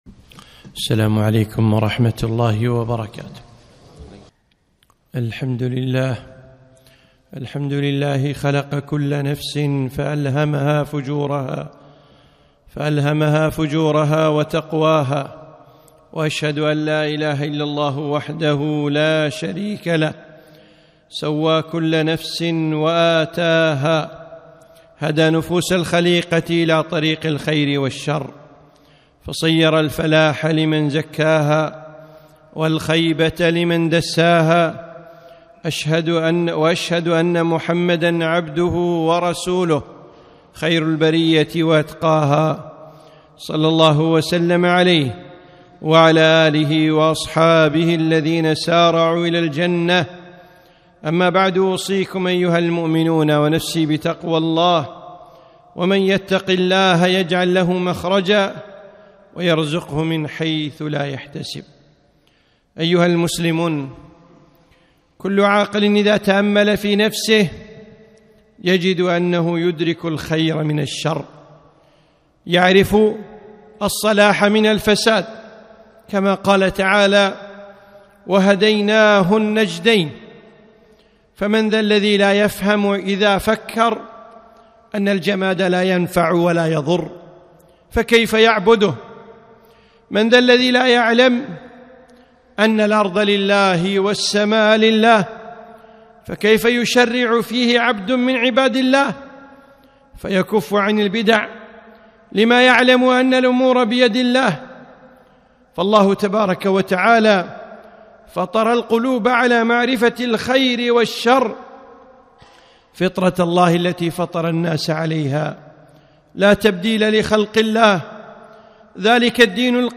خطبة - اختر لنفسك الطريق